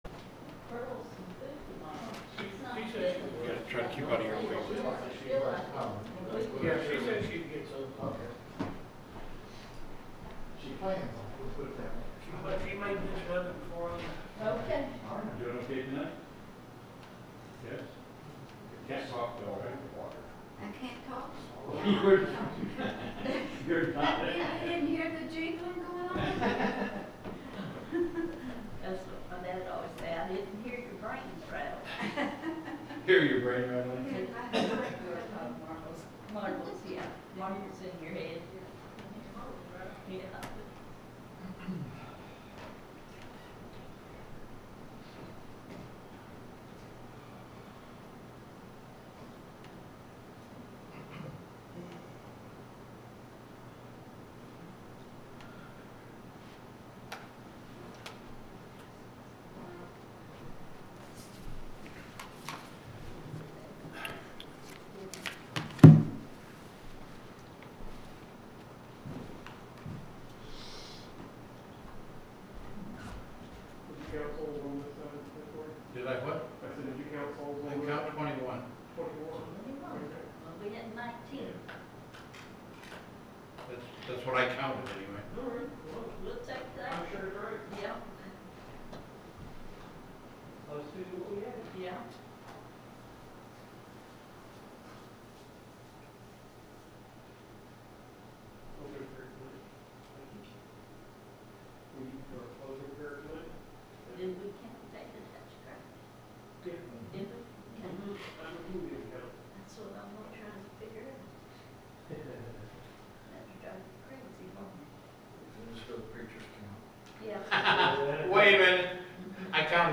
SUNDAY PM WORSHIP
The sermon is from our live stream on 11/9/2025